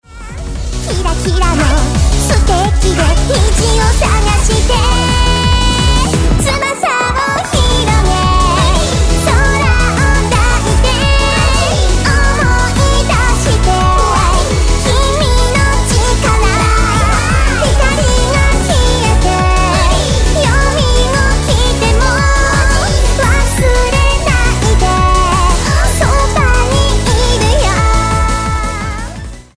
その萌え萌えボイスで元気いっぱいに歌い上げてくれるぞ！
まるでスーパーヒロインタイムみたいなノリノリの楽曲だ。